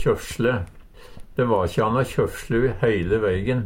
kjøfsjle - Numedalsmål (en-US)